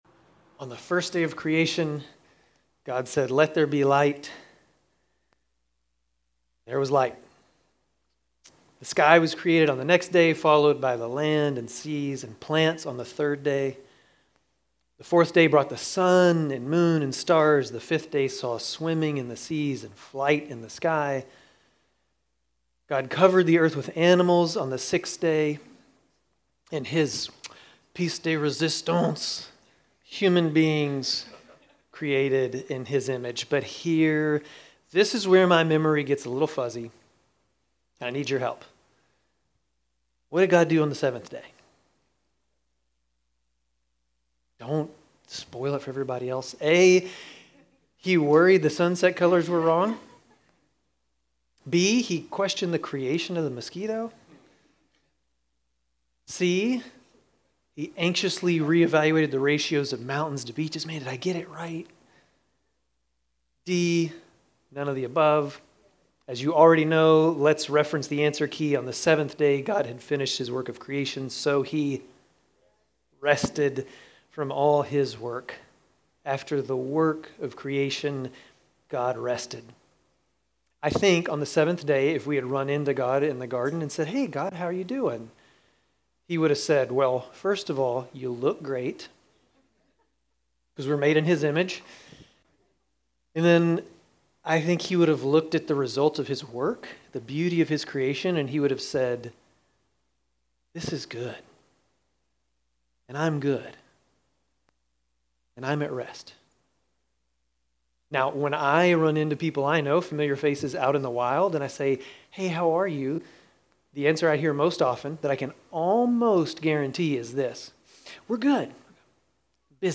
Grace Community Church Dover Campus Sermons 10_12 Dover Campus Oct 13 2025 | 00:26:39 1x 00:00 / 00:26:39 Subscribe Share RSS Feed Share Link Embed